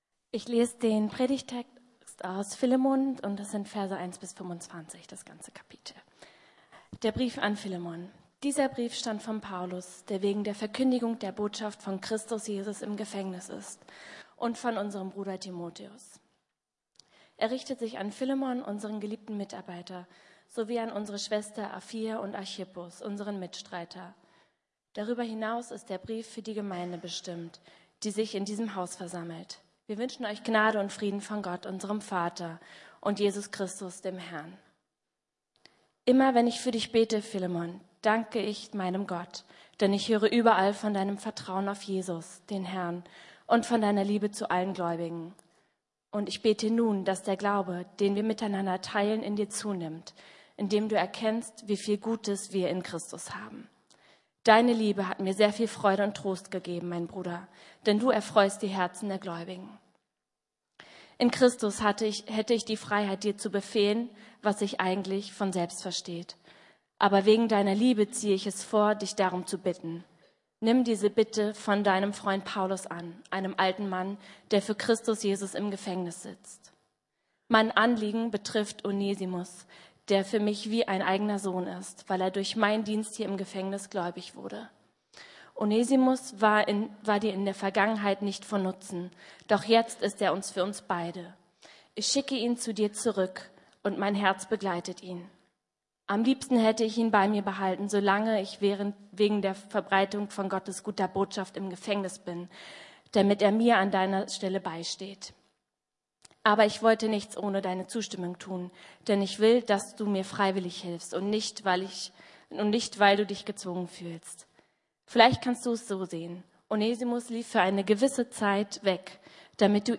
Das Erbe der Zukunft (1) Neues Leben ~ Predigten der LUKAS GEMEINDE Podcast